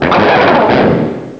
pokeemerald / sound / direct_sound_samples / cries / gurdurr.aif